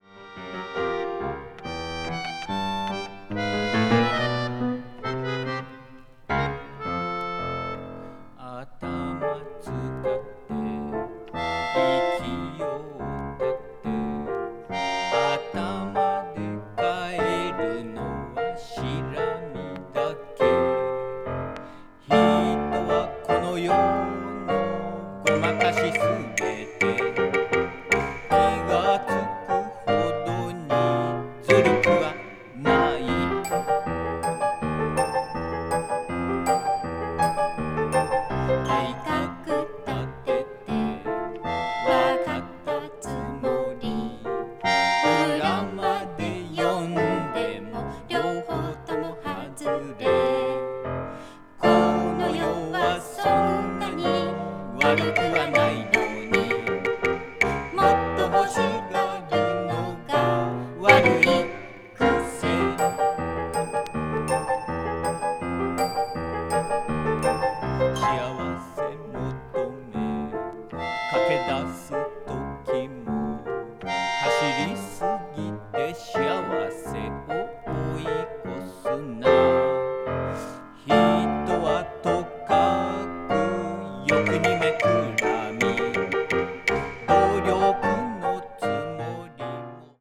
media : NM-/NM-(一部わずかにチリノイズが入る箇所あり)